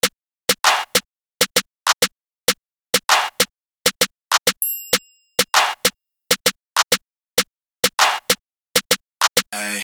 Crafted for Dark Hoodtrap & Underground Beats.
•Drum Fills & Perc Loops to enhance your drum bounce with ease.
mink_perc_loop_196_bpm.mp3